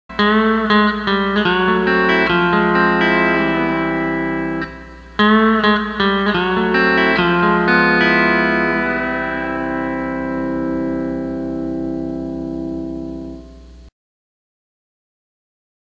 Steel Guitar Tab Page 2
C6th - Open string roll with 2 versions Tab